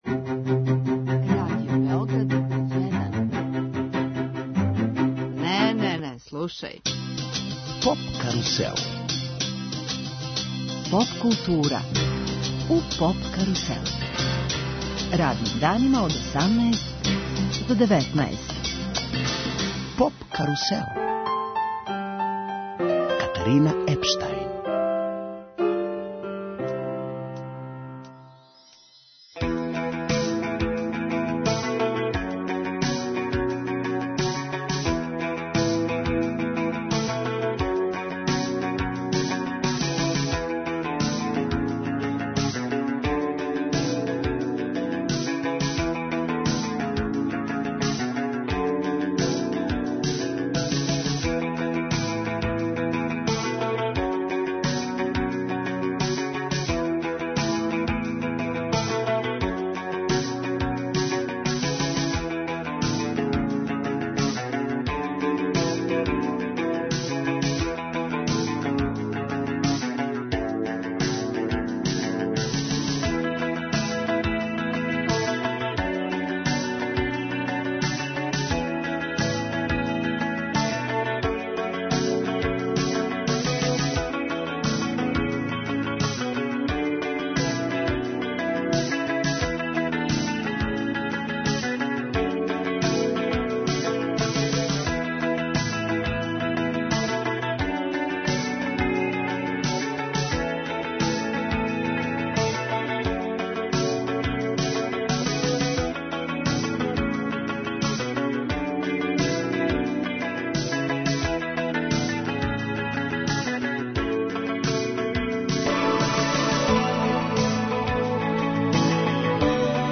Поп карусел емитује се уживо, са фестивала EXIT.